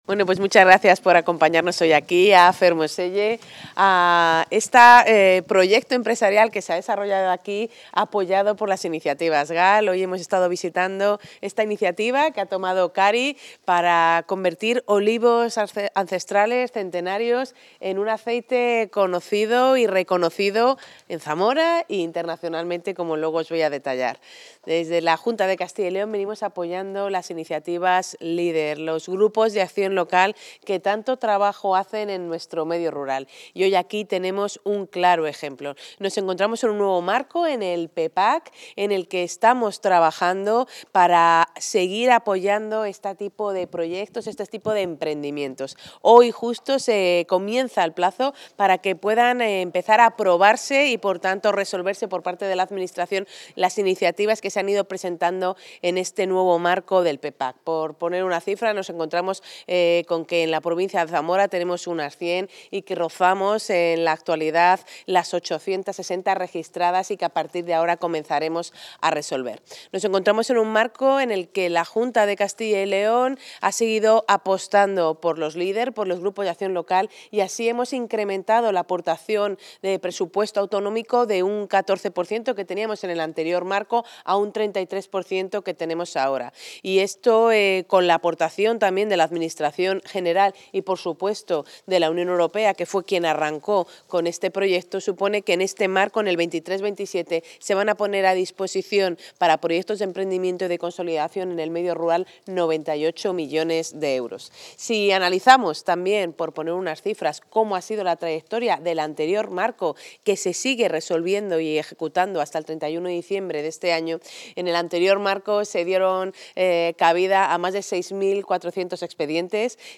Intervención de la consejera.
La consejera de Agricultura, Ganadería y Desarrollo Rural visita una almazara en Fermoselle, Zamora, financiada con estos fondos europeos como ejemplo del apoyo que se ofrece a las pequeñas industrias agroalimentarias.